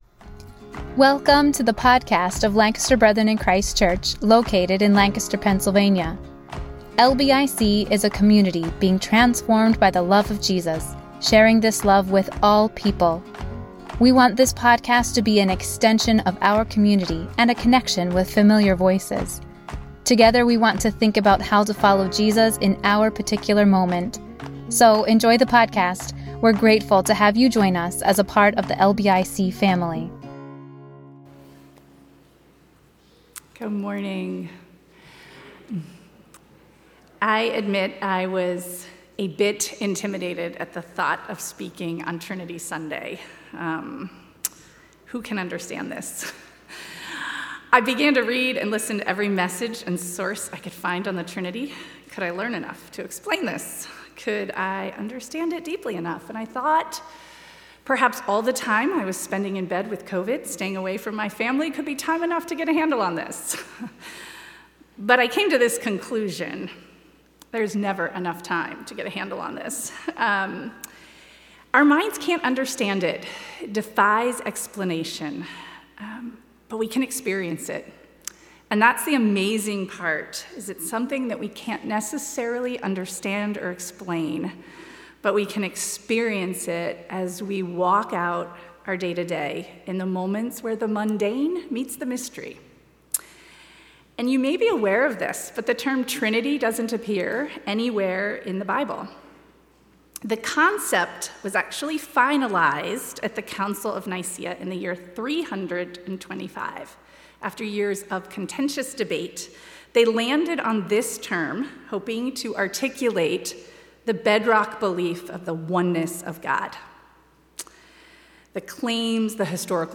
A message from the series "Ordinary Time."